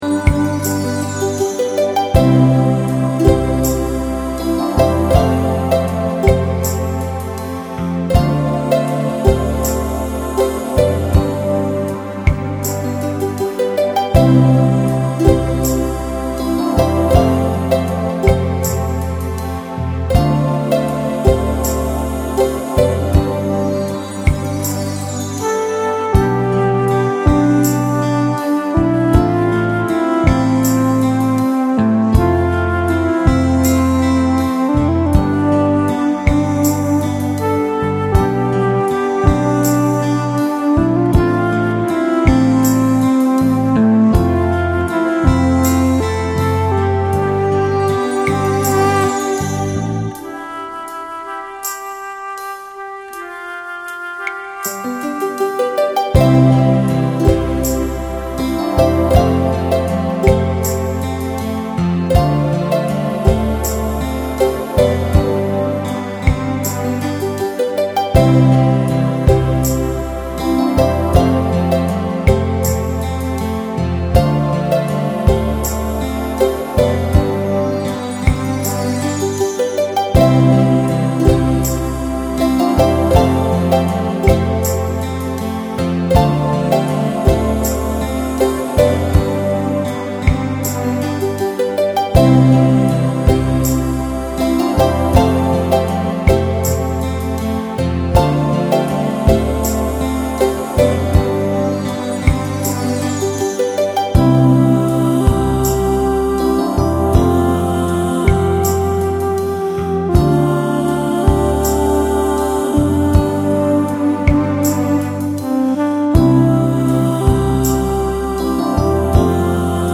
Musik f�r Urlaubsstimmung, konservativ, Zielgruppe 40+